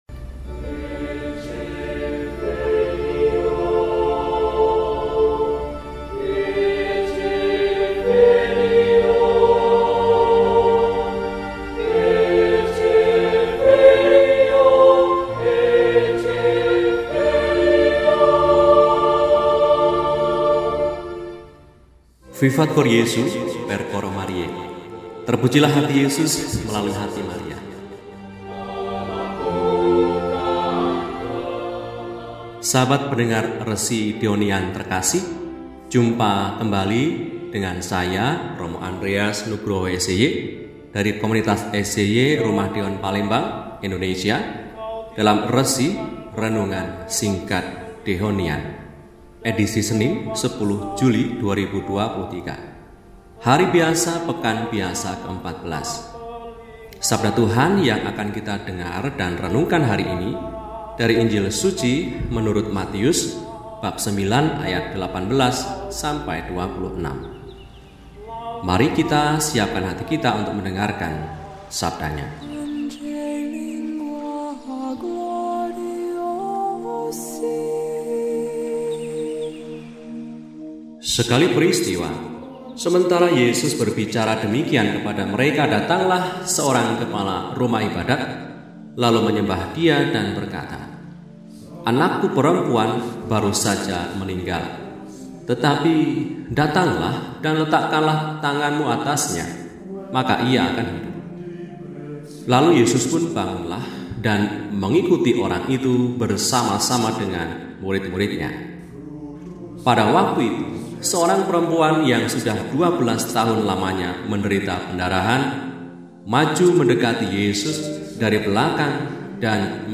Senin, 10 Juli 2023 – Hari Biasa Pekan XIV – RESI (Renungan Singkat) DEHONIAN